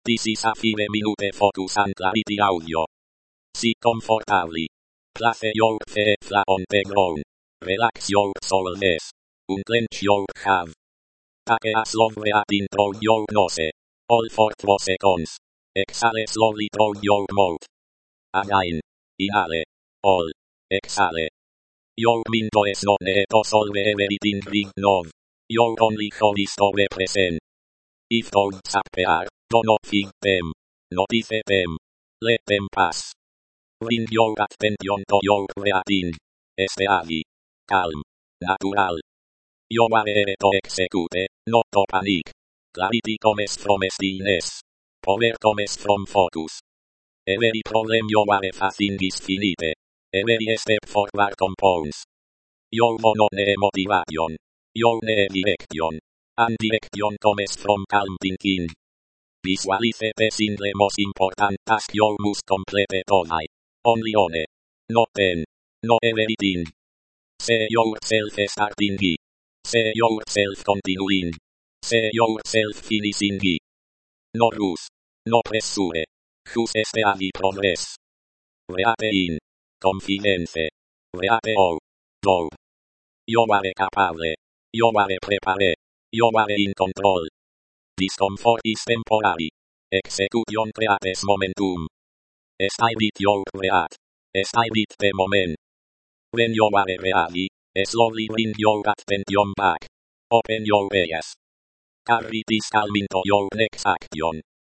AUDIO BRIEFS
Professional-grade narration designed for busy specialists.